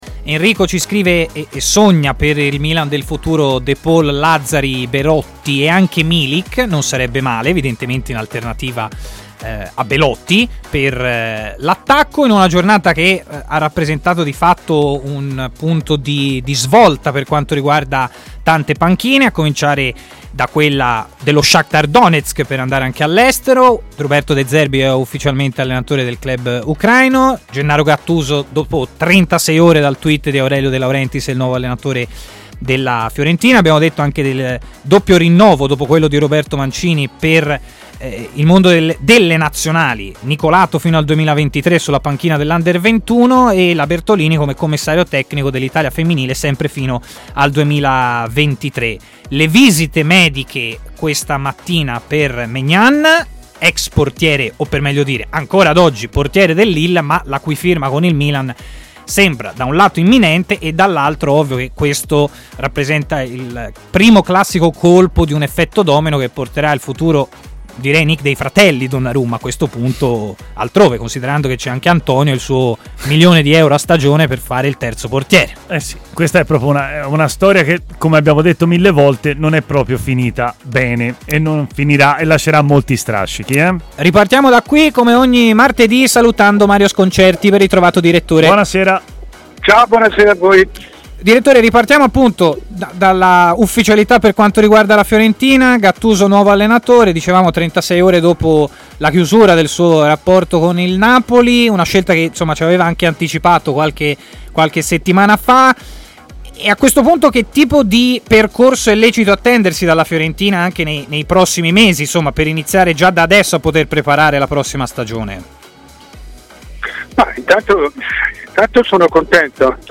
Mario Sconcerti, prima firma del giornalismo sportivo italiano, ha parlato a Stadio Aperto, trasmissione di TMW Radio